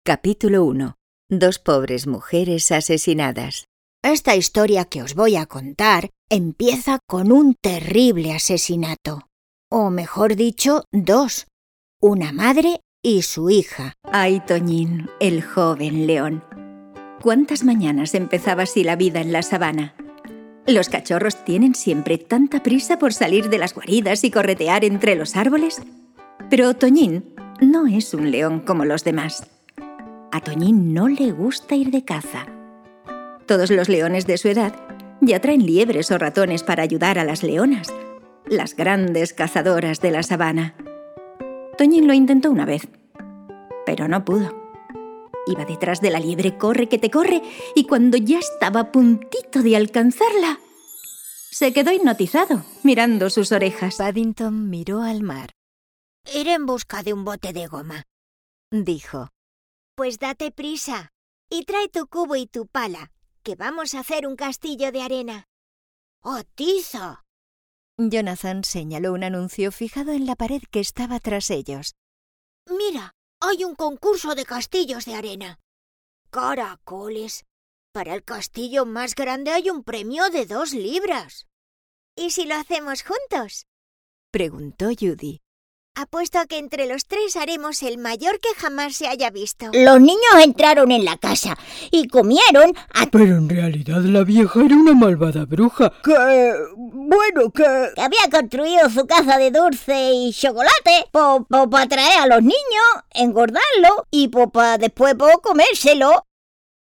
Female
Bright, Character, Children, Natural, Warm, Versatile, Confident
People say my voice sounds very clear, warm, trustworthy and expressive and that it can also sound fun, educational and informative…
Microphone: Neumann TLM 103, Rode NT1A & Blue Microphones Yeti USB